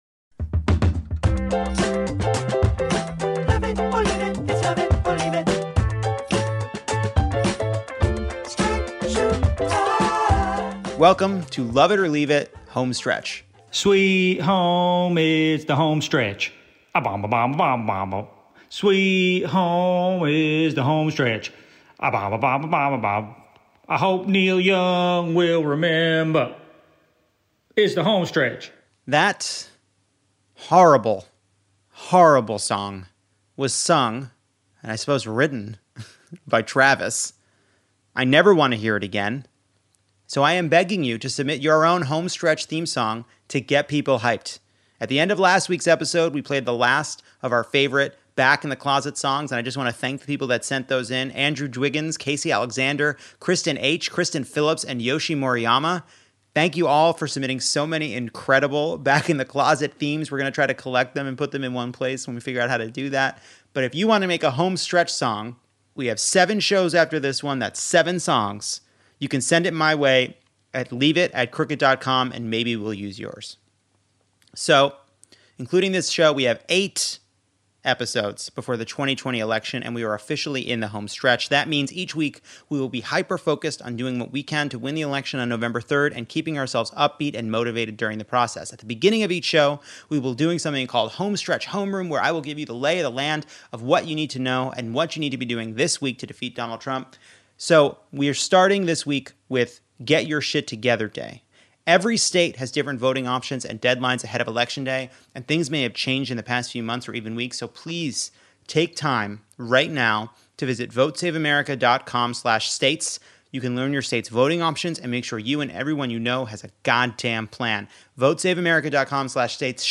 What you don't know that Trump told Bob Woodward can hurt you. For our first homestretch episode, Jon Favreau joins for the monologue
Plus listeners check on their parents' voter registrations and I quiz a listener on whether you can tell the difference between awful Trump comments on the military or ones we just made up.